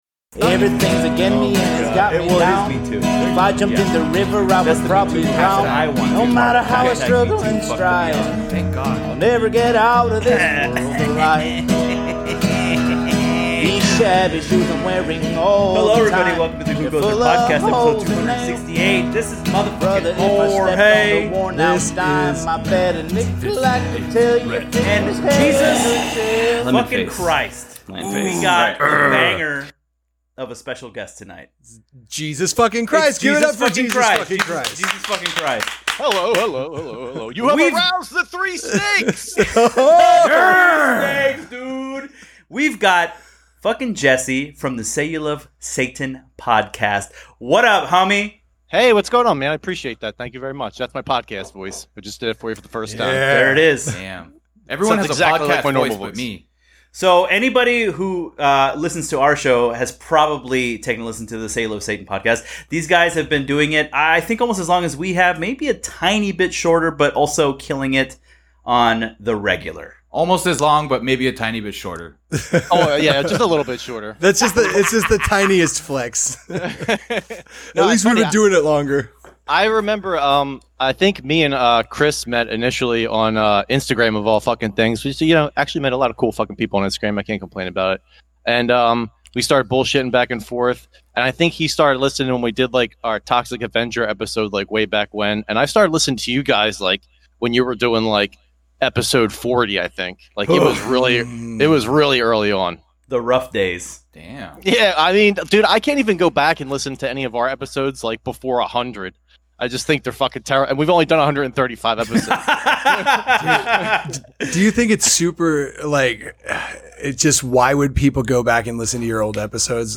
(Unfortunately the intro plays over the first few seconds, but you don't miss anything.